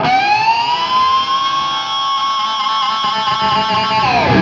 DIVEBOMB18-R.wav